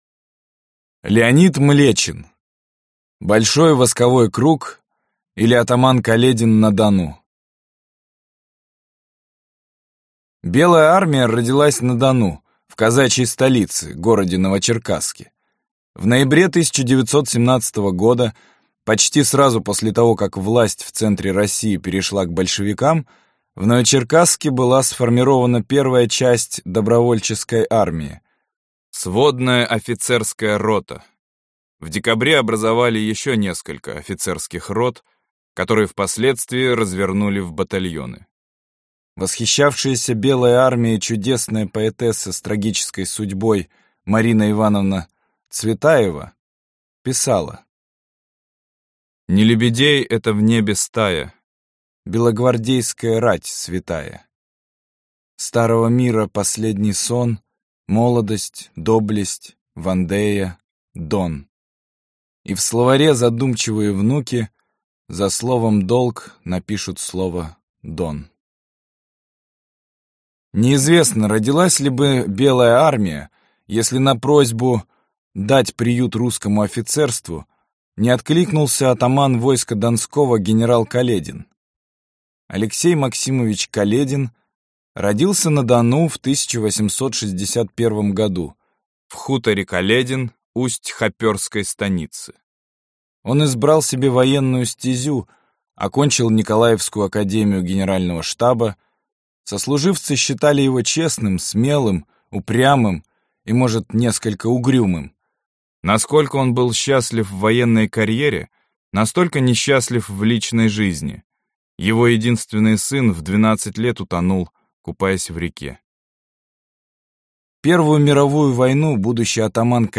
Аудиокнига Белый и красный террор | Библиотека аудиокниг